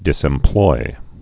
(dĭsĕm-ploi)